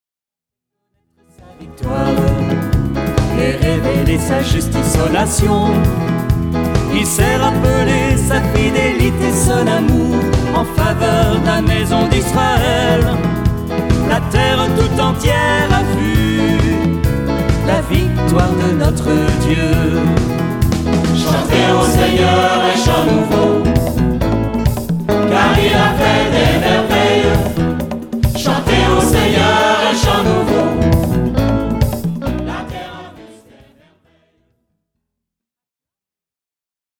Utilisant des arrangements très colorés
mélodies simples